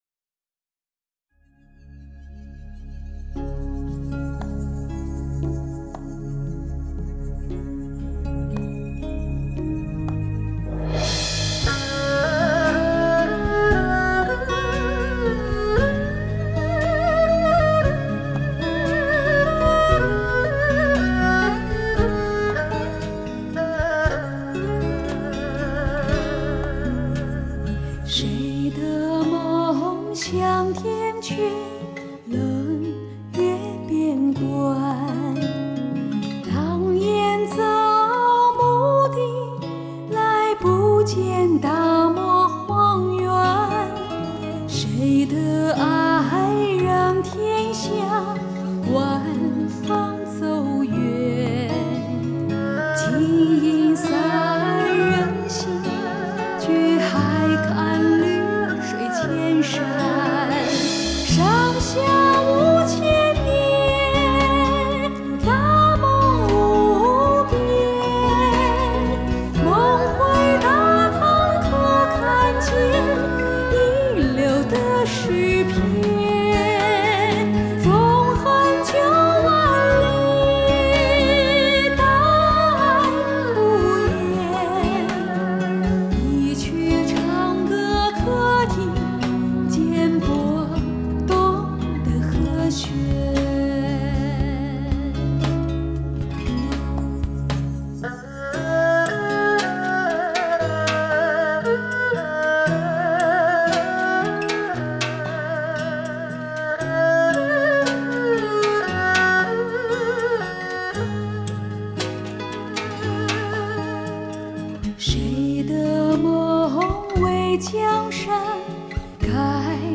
咤叱唱片界的DTS环绕技术，以无可比拟的高保真度和空间立体感，风靡了所有的“听觉领域”。
婉转流畅的曲调，
晶莹飘盈的唱腔，令你义无反顾的堕入民歌的深谷，而不愿自拔。